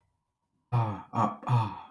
These are percussive consonants, where the sound is generated by one organ striking another.
Percussive consonants
Voiceless_bilabial_percussive.wav.mp3